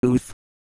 Worms speechbanks
ooff1.wav